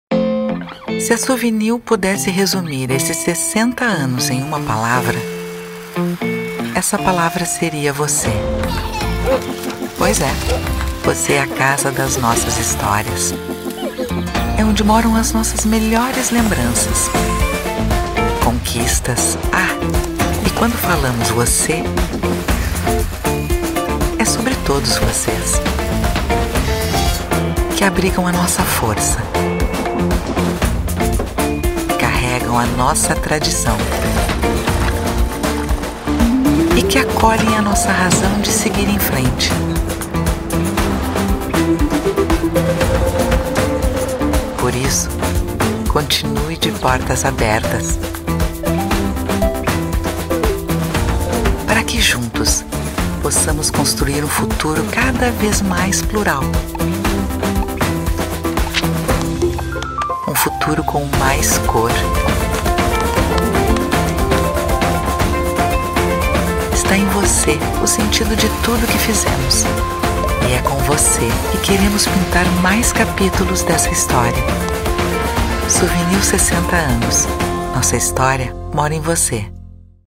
Feminino
Voz Padrão - Grave 01:20